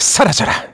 Riheet-Vox_Skill1_kr-02.wav